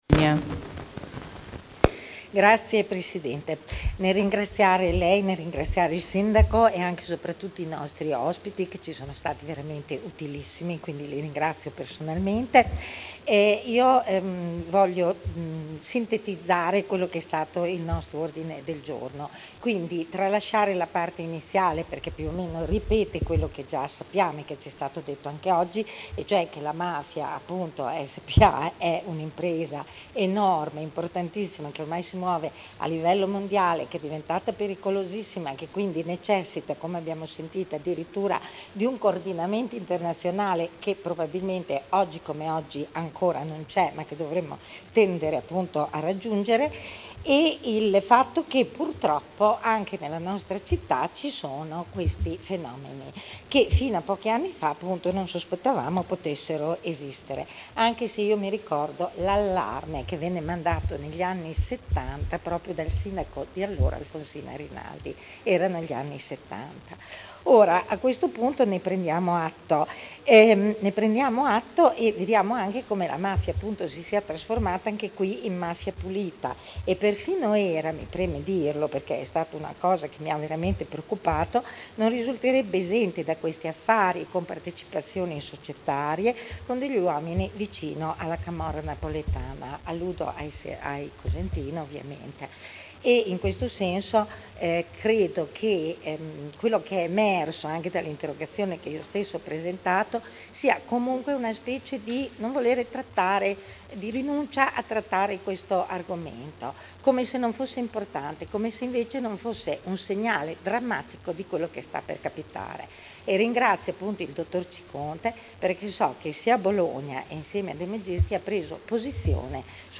Intervento del Consigliere Eugenia Rossi al Consiglio Comunale su: Politiche di prevenzione e contrasto alle infiltrazioni mafiose.